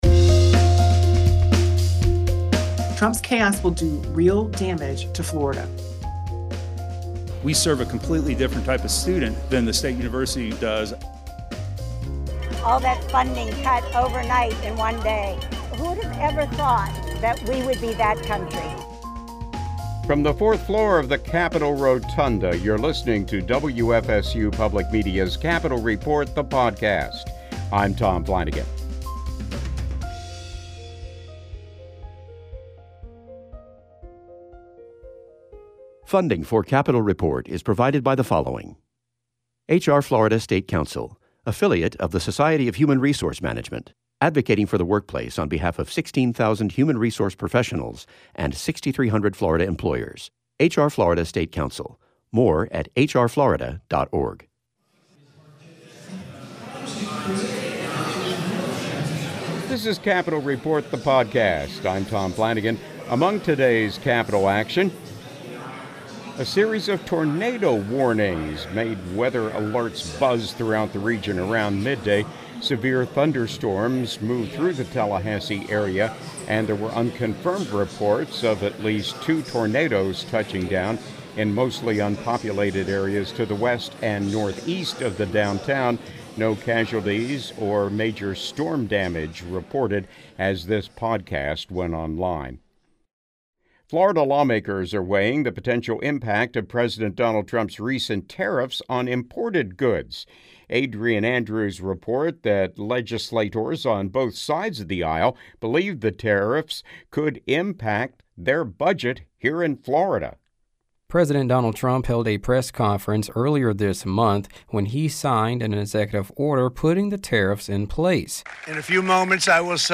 WFSU Public Media reporters, as well as reporters from public radio stations across the state, bring you timely news and information from around Florida. Whether it's legislative maneuvers between sessions, the economy, environmental issues, tourism, business, or the arts, Capital Report provides information on issues that affect the lives of everyday Floridians.